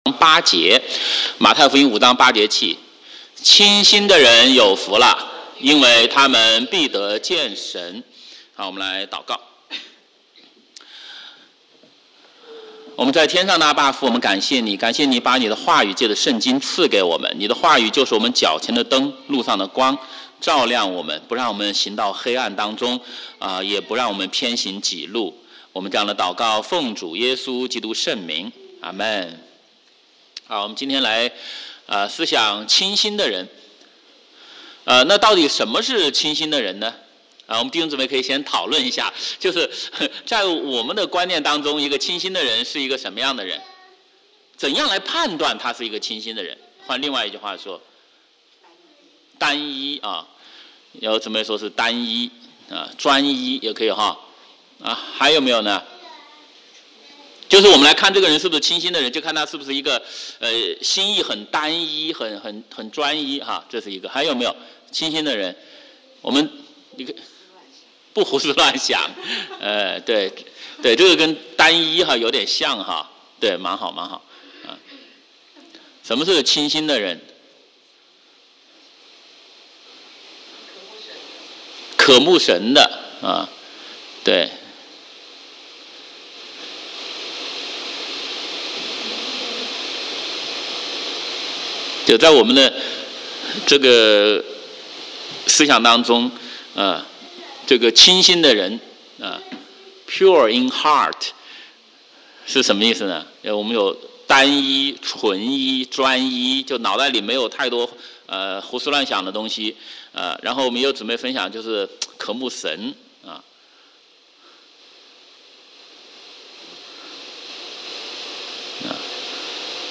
Adult Sunday school:Sundays @ 9:30am